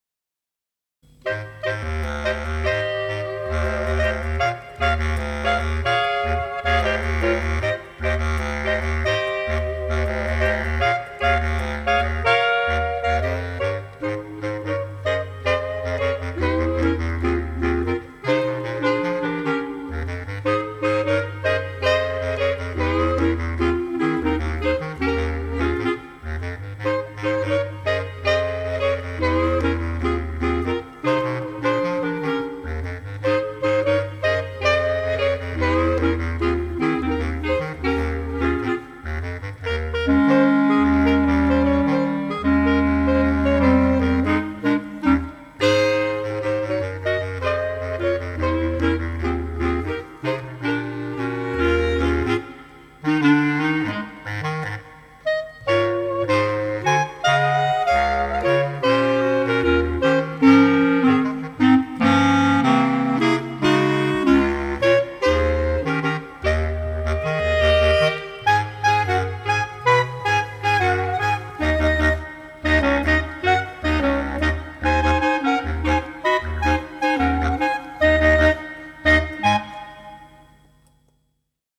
is set in a funky Latin groove.
Bass Clarinet Lowest Note: E1.
Level: Beginner-Intermediate.